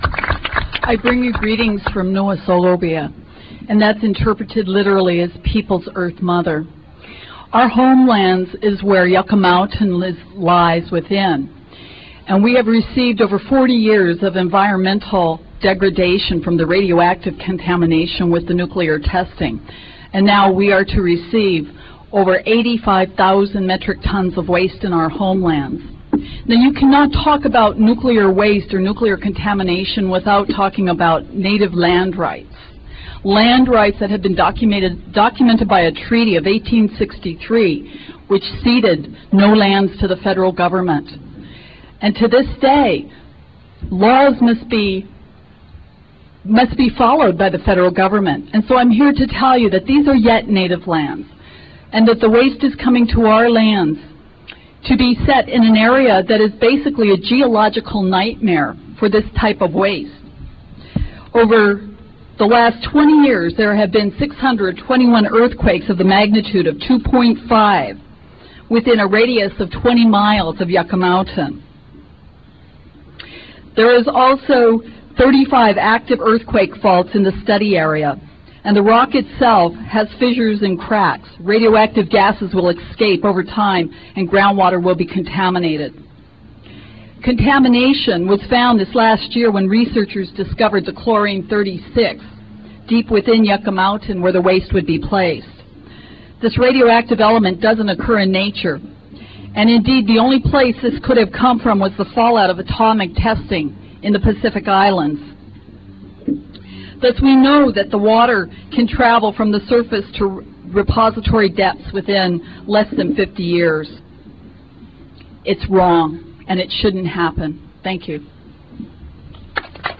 lifeblood: bootlegs: 1997-09-24: honor the earth press conference - washington, d.c.